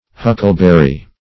Huckleberry \Huc"kle*ber`ry\, n. [Cf. Whortleberry.] (Bot.)